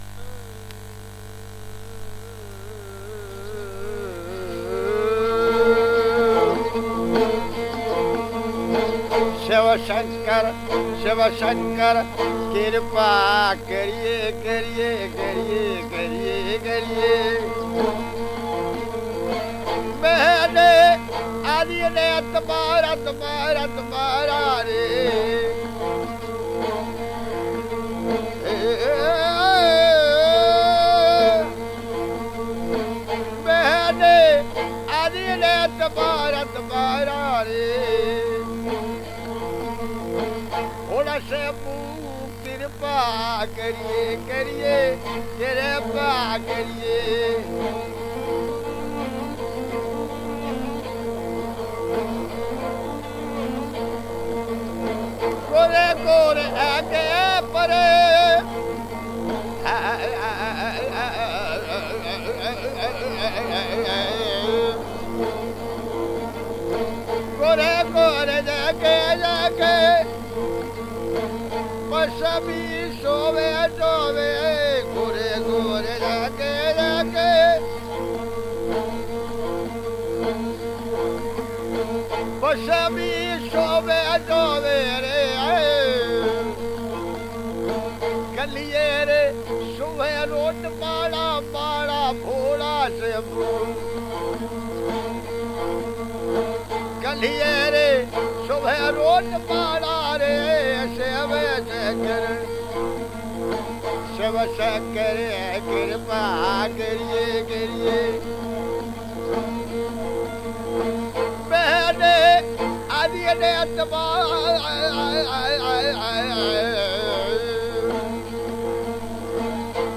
ロマ（ジプシー）の起源でもあるラジャスタン州のストリート・ミュージックを収録した音楽集！
※レコードの試聴はノイズが入ります。